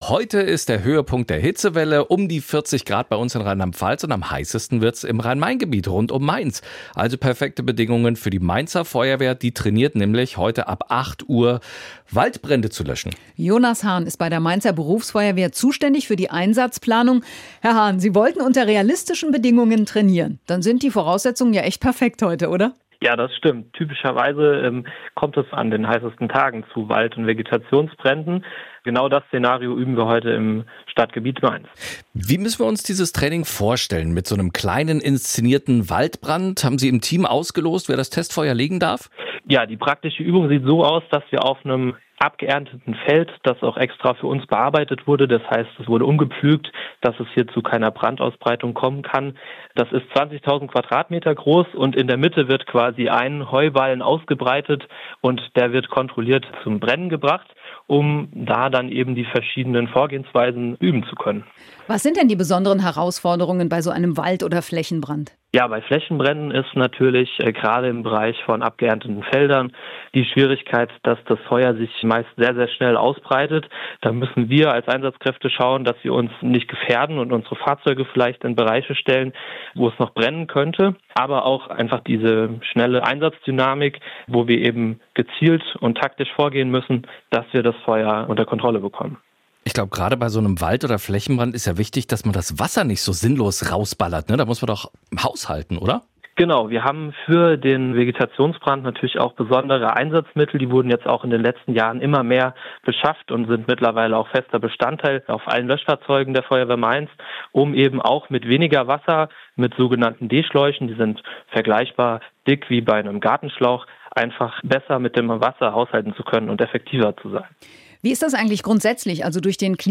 SWR1 Interview